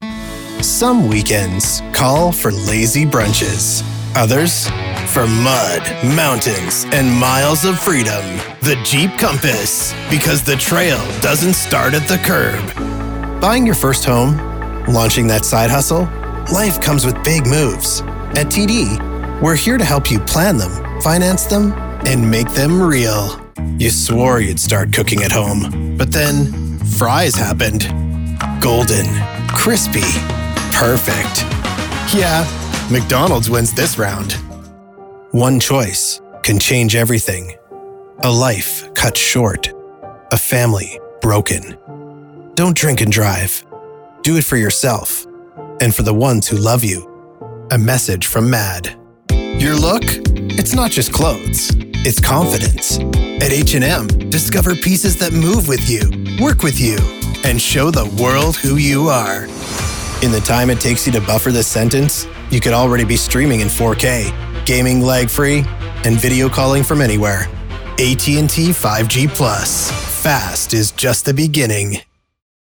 Radio & TV Commercial Voice Overs Talent, Artists & Actors
Yng Adult (18-29) | Adult (30-50)